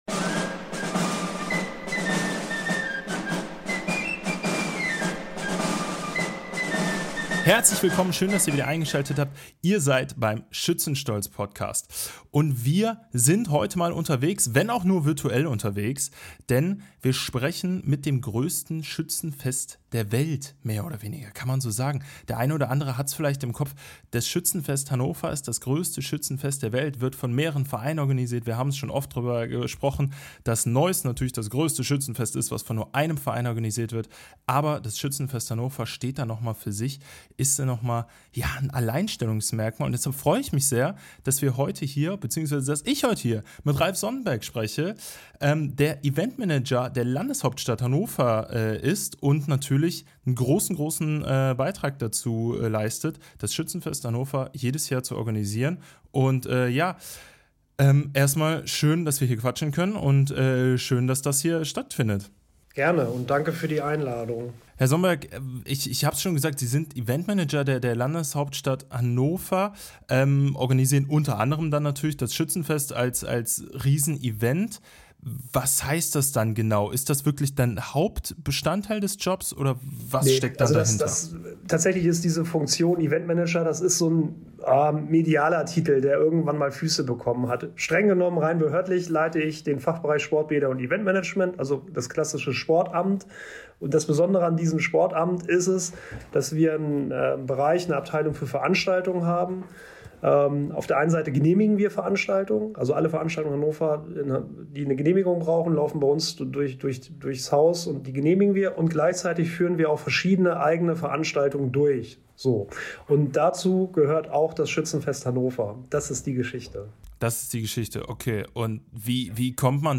Ein Gespräch über professionelle Veranstaltungsplanung, klare Zuständigkeiten und das Zusammenspiel von Verwaltung und Ehrenamt bei einem Großereignis mit internationaler Strahlkraft.